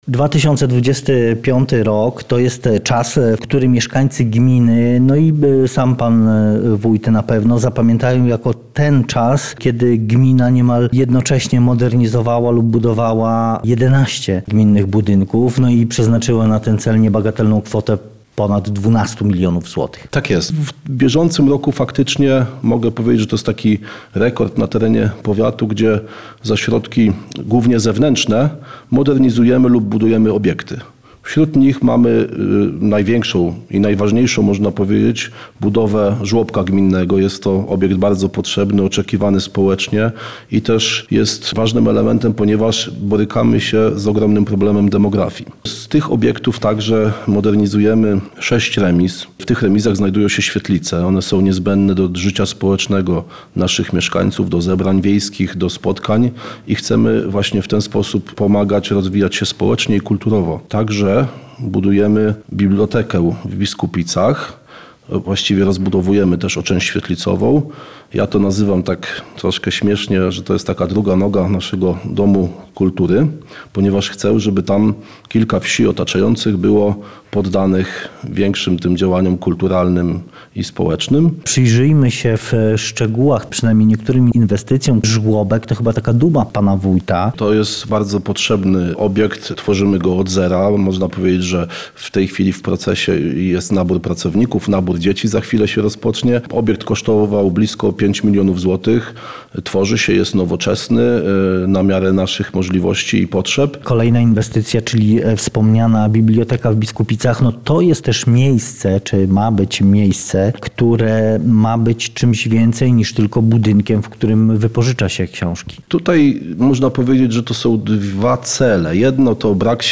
Damian Daniel Baj, wójt gminy Trawniki opowiada w rozmowie